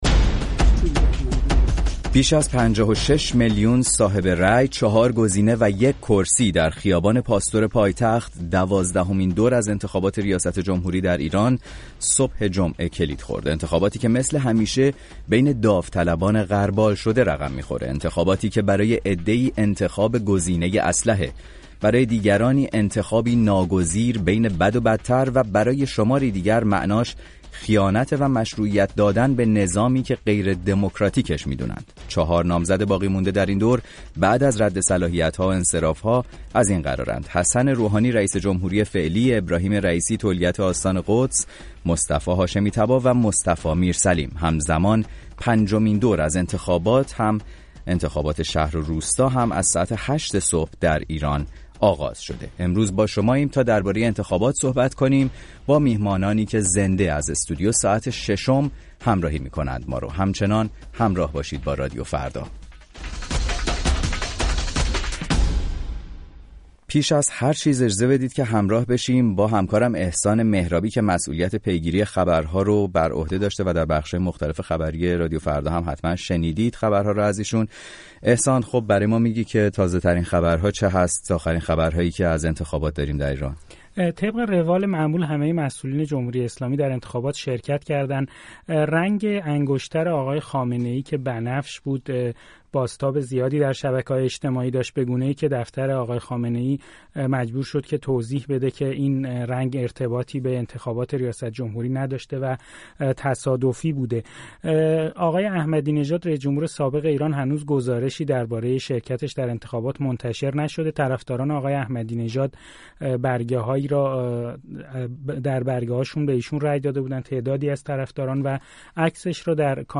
همزمان با برگزاری انتخابات، ویژه برنامه انتخاباتی ساعت ششم میزبان مخاطبان رادیو فردا و چهار تحلیلگر سیاسی با نظرات مخالف بود.